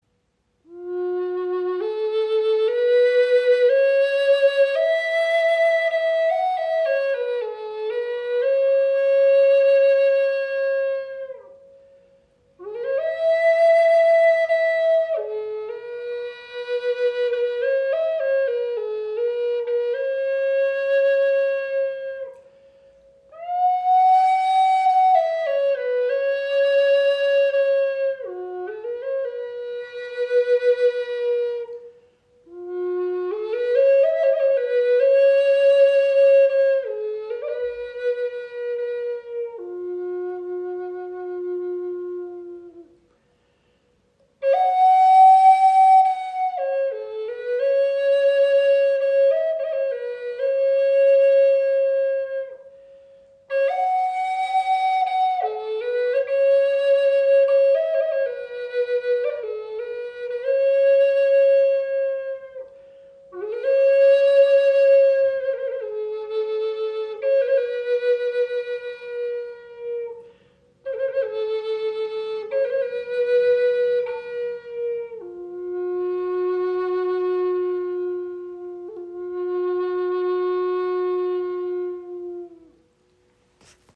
• Icon Gesamtlänge 55  cm, 22  mm Innenbohrung – harmonischer Klang
Diese handgefertigte Gebetsflöte in F# (432 Hz) vereint Palisander mit Akzenten aus Safou-Holz (African Pear) und einem abstrakt gestalteten Windblock – eine harmonische Kombination, die sowohl optisch als auch klanglich beeindruckt.
Sie entfaltet einen klaren, vollen und kraftvollen Ton, der zugleich präsent, warm und meditativ weich ist.
Mit 55 cm Länge und einer Innenbohrung von 22 mm liegt die Flöte angenehm in der Hand, spricht leicht an und entfaltet klare, harmonische Töne.